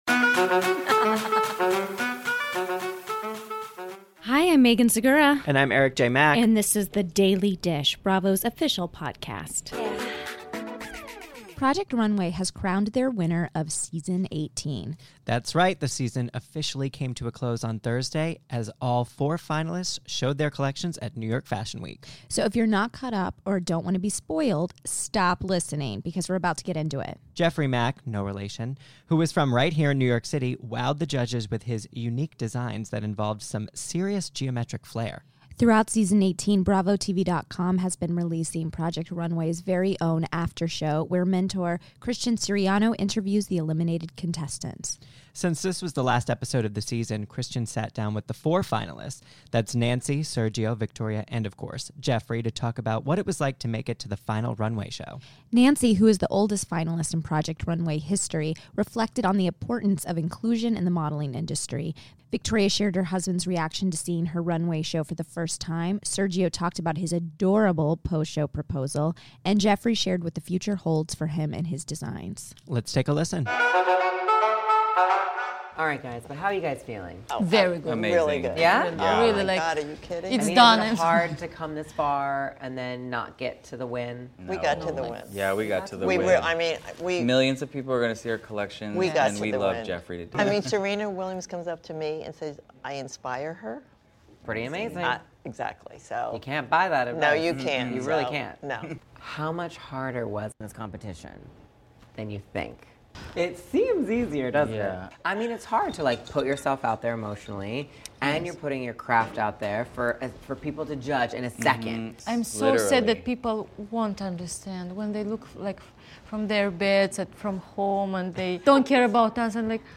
Christian Siriano Interviews Project Runway’s Season 18 Finalists